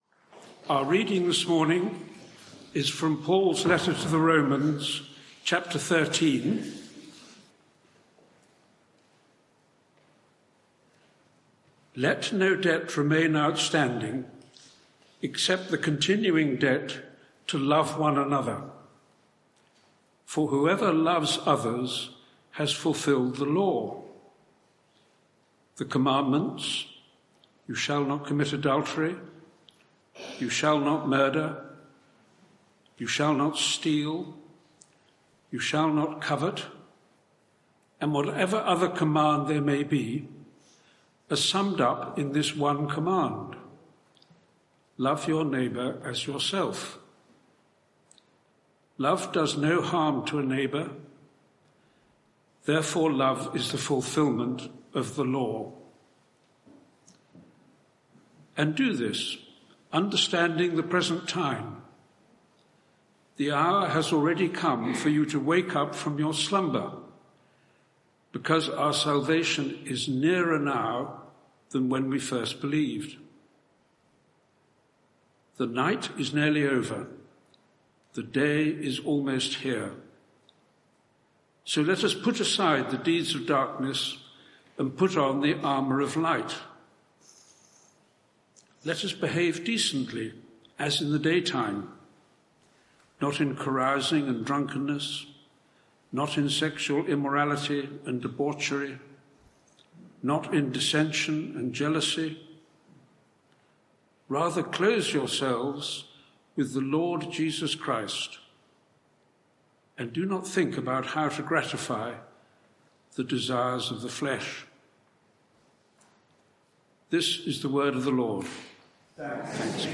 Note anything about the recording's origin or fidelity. Media for 11am Service on Sun 17th Nov 2024 11:00 Speaker